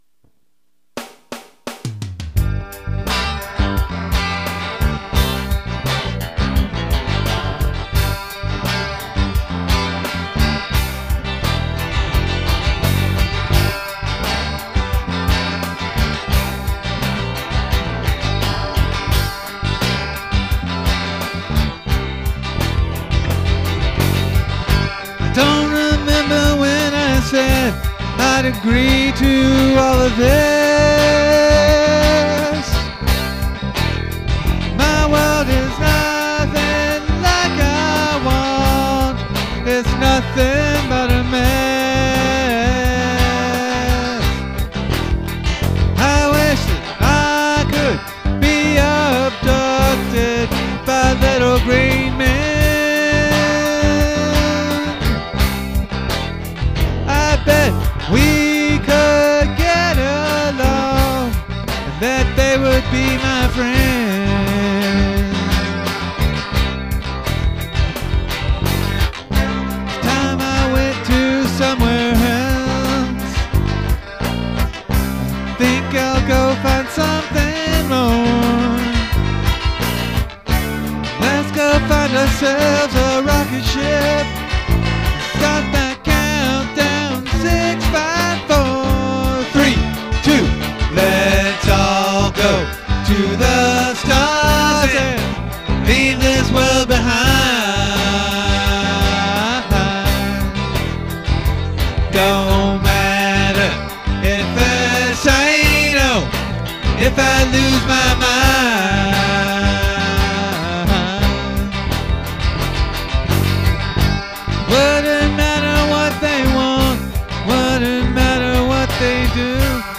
Unless otherwise noted, they're all originals, and I'm playing everything and singing.
The drums I've programmed as quickly as possible, but it's mostly a groove quantize over a couple of bass patterns.